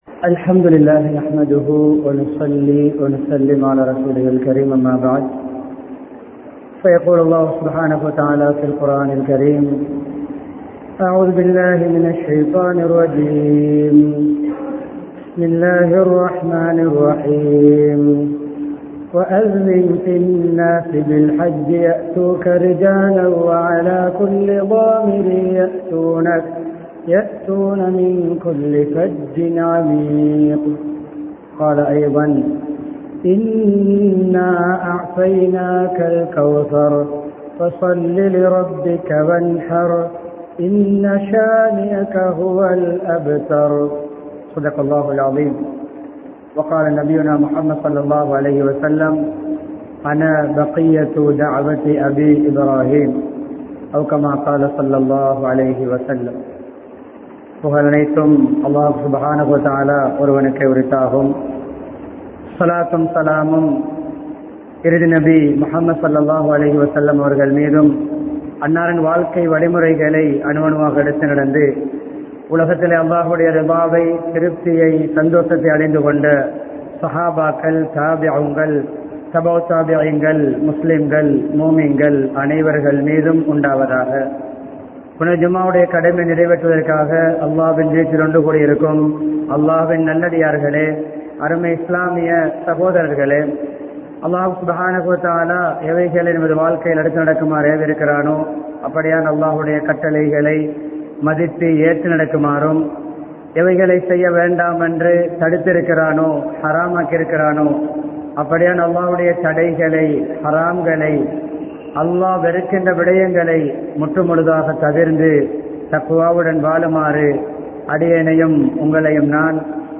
நபி இப்றாஹீம்(அலை) அவர்களின் வாழ்வியல் முறை | Audio Bayans | All Ceylon Muslim Youth Community | Addalaichenai
Ar Rahmath Jumua Masjidh